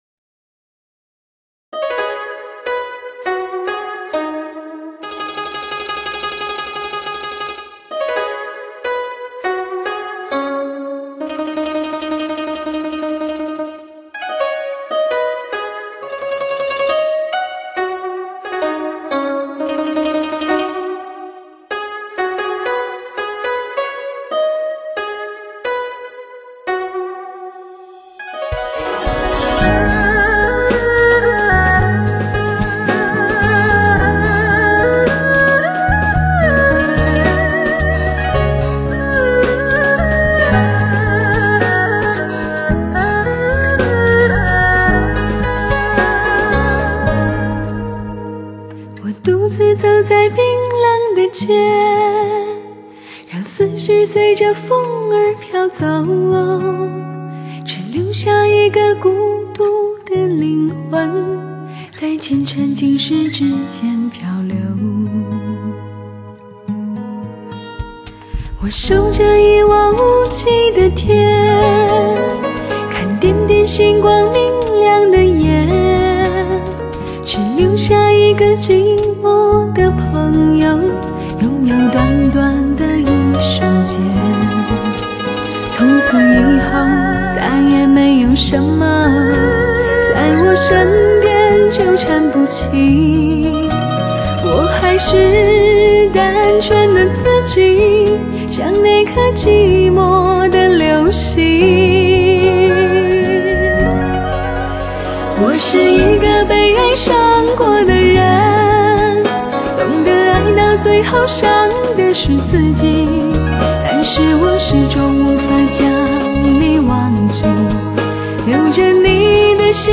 HIFI天簌发烧